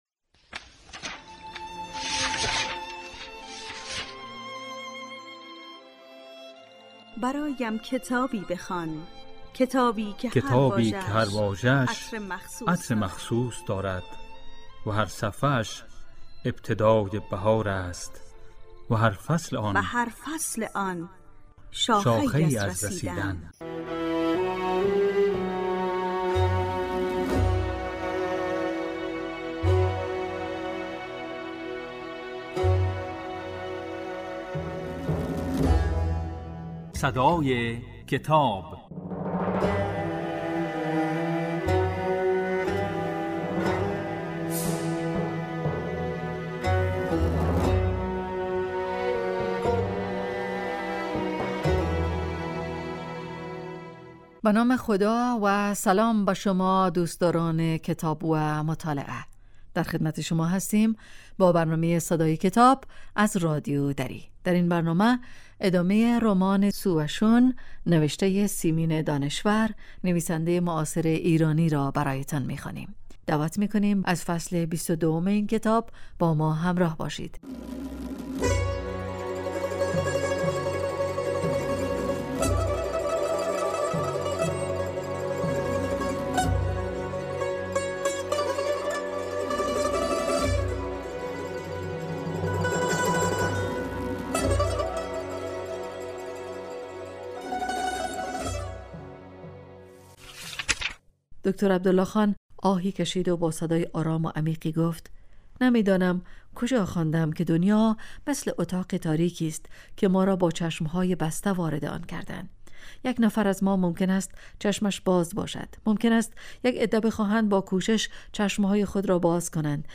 در این برنامه، به دنیای کتاب‌ها گام می‌گذاریم و آثار ارزشمند را می‌خوانیم.